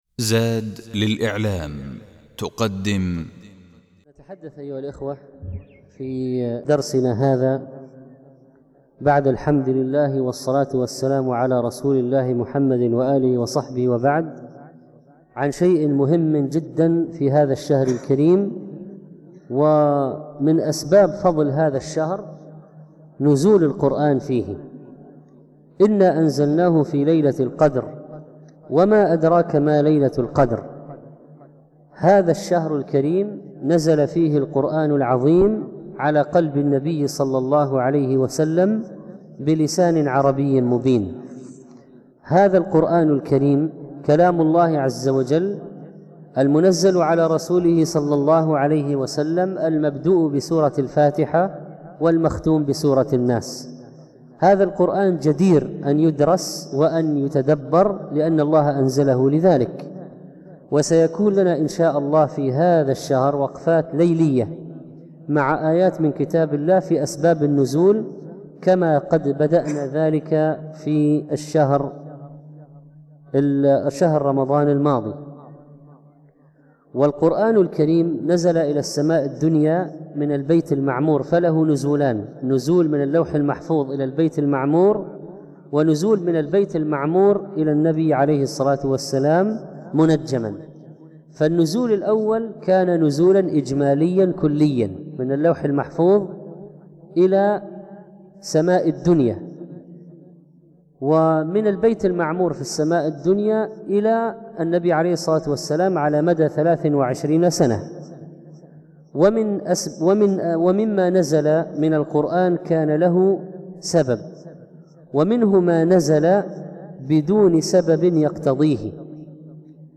شيء مهم في رمضان في هذا الدرس يتحدث الشيخ عن شيء مهم في شهر رمضان، ومن أسباب فضل هذا الشهر: نزول القرآن فيه،